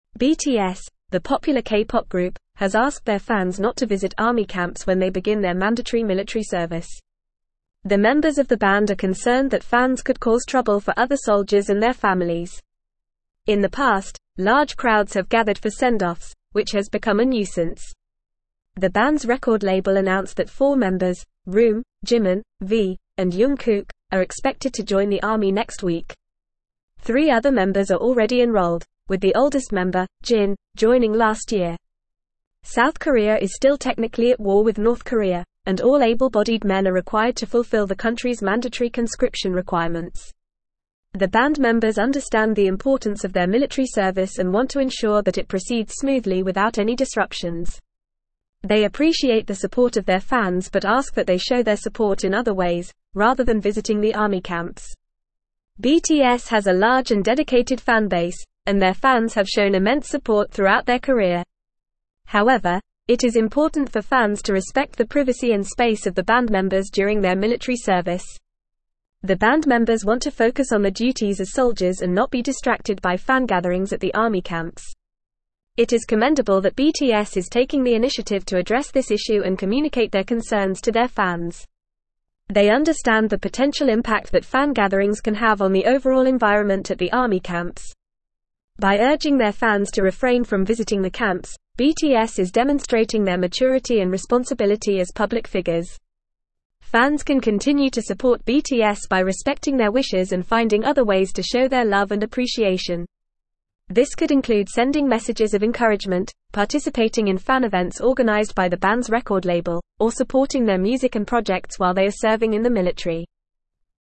Fast
English-Newsroom-Advanced-FAST-Reading-BTS-Urges-Fans-to-Stay-Away-from-Army-Camps.mp3